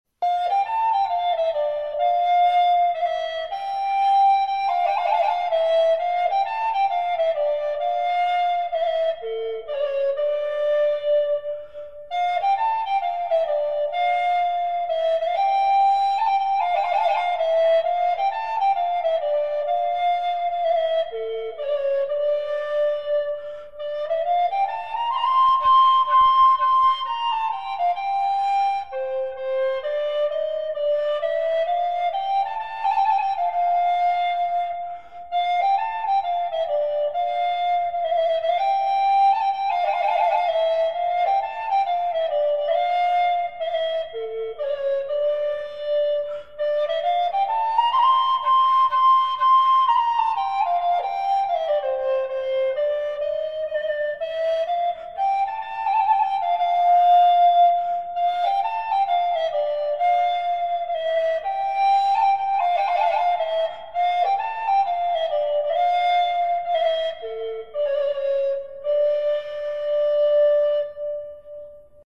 ヴェルサイユ・リコーダー
音色を聞いてみる
楽器：European Boxwoodのエコ・リコーダー（上写真）